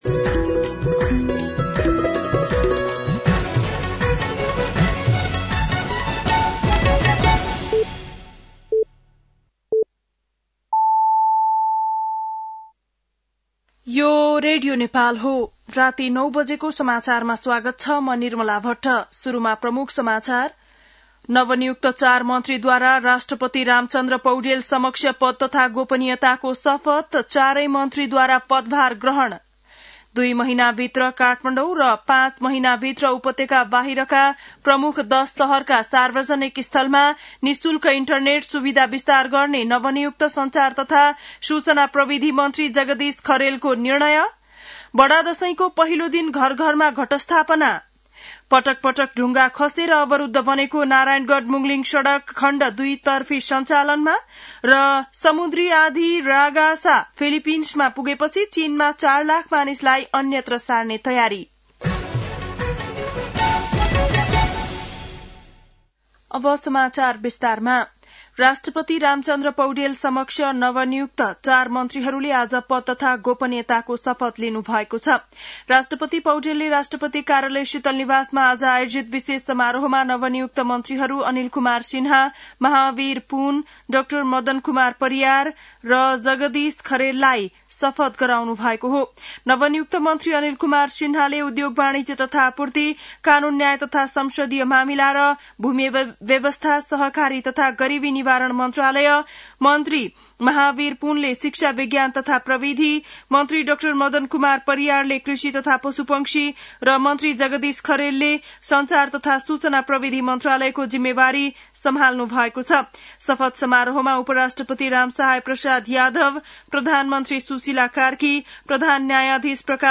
बेलुकी ९ बजेको नेपाली समाचार : ६ असोज , २०८२
9-PM-Nepali-NEWS-6-06.mp3